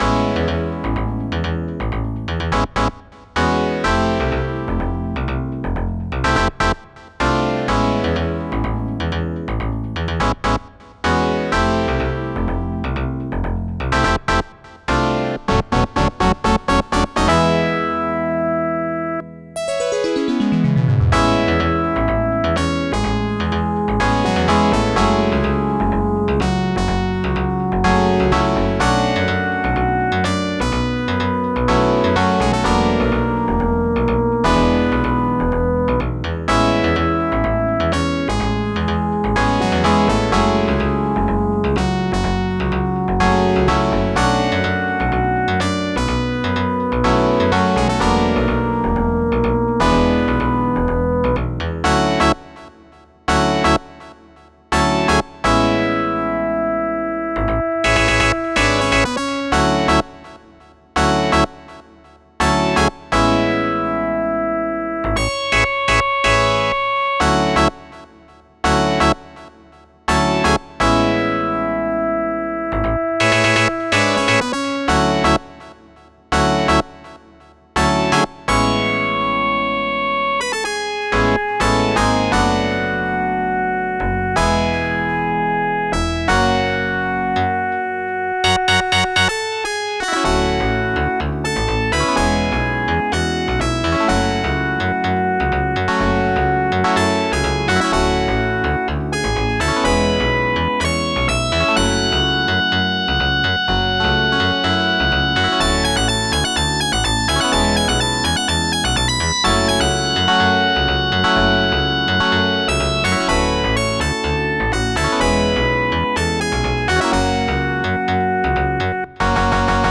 I made a script that converts raw YM2151 registers to bank formats like OPM.
They're almost identical.